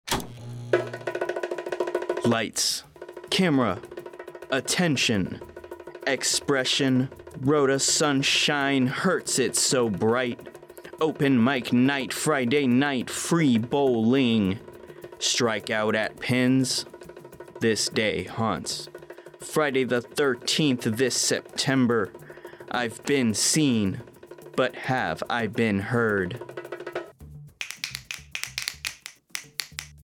AFN Radio Spot: Open Mic Night at Pinz